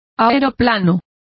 Complete with pronunciation of the translation of airplane.